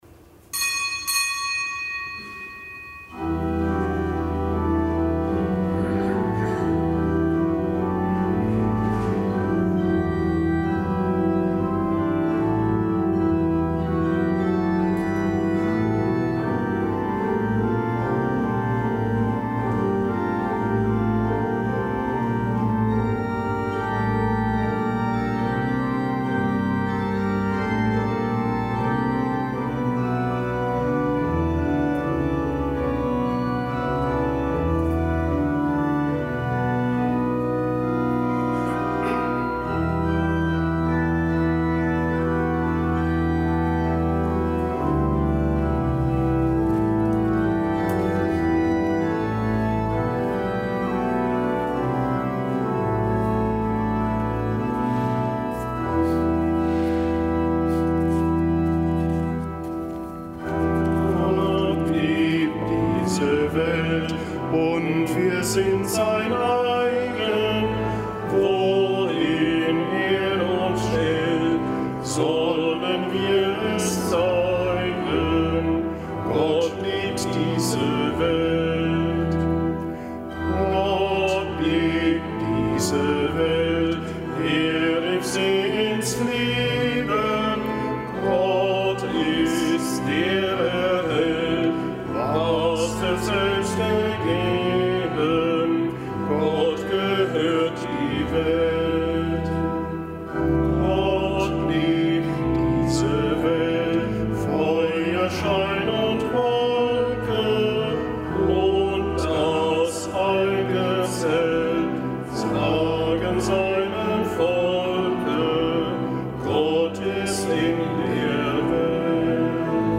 Kapitelsmesse aus dem Kölner Dom am Gedenktag Heiligen Antonius von Padua, Ordenspriester und Kirchenlehrer.